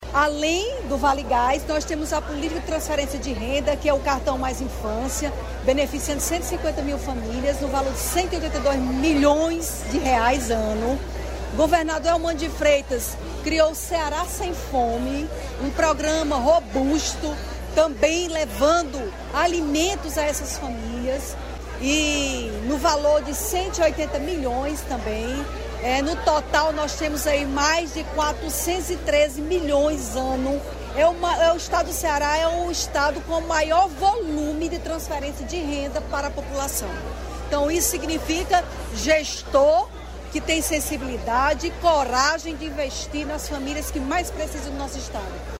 Entrevista Secretária Onélia Santana:
entrevista-secretaria-onelia.mp3